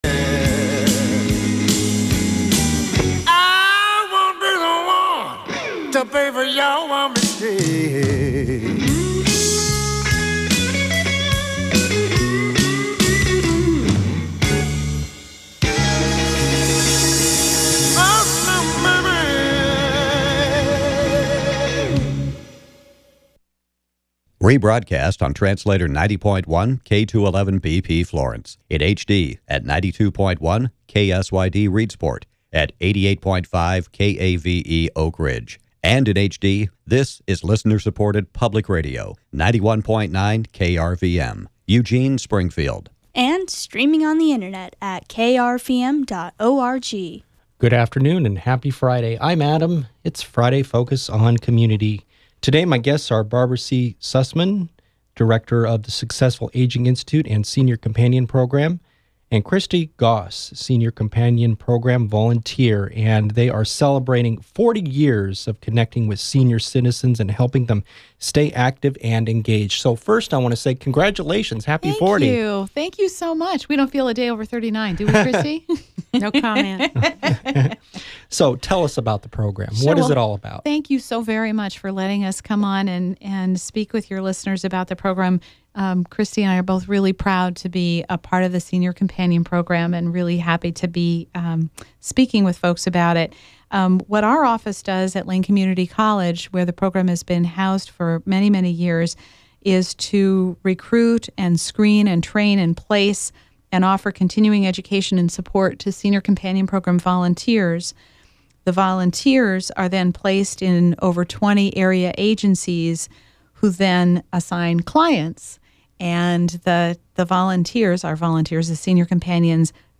KLCC Interview October 17, 2014 short musical interlude precedes the interview iListen to the KRVM interview with Senior Companion Program volunteer